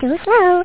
Amiga 8-bit Sampled Voice
1 channel
tooslow.mp3